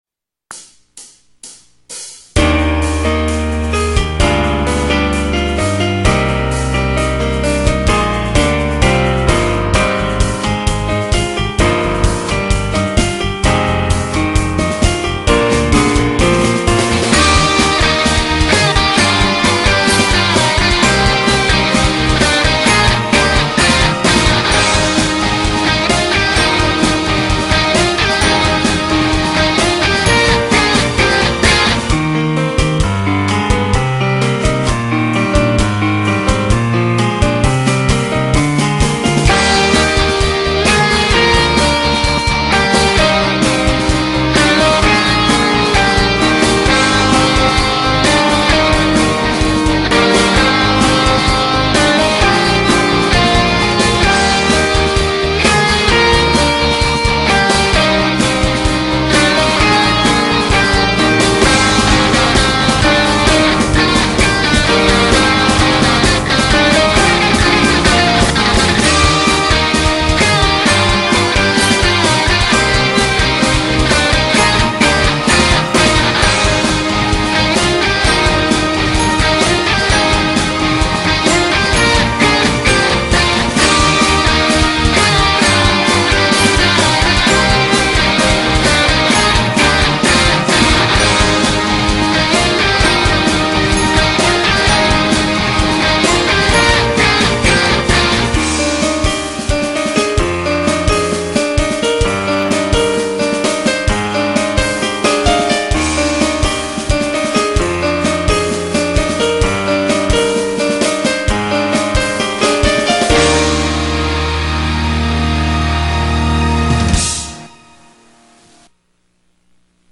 퓨젼 곡입니다.